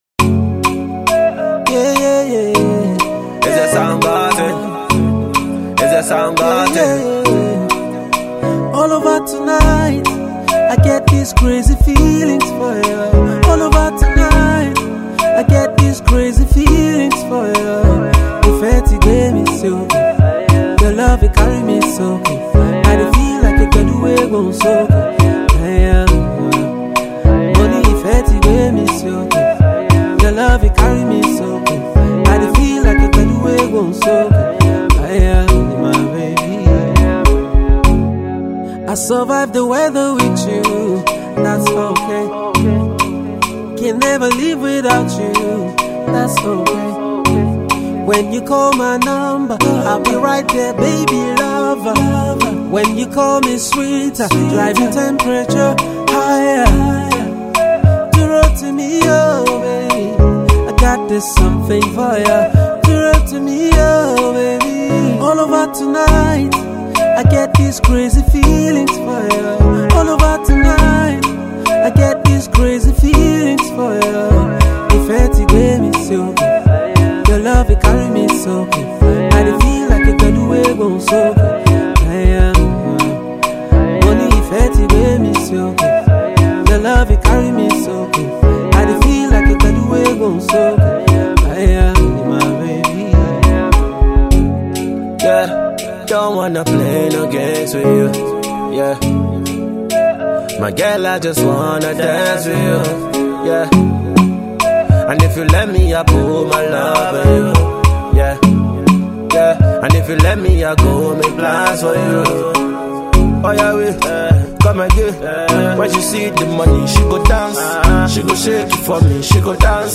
Pop / R &B fusion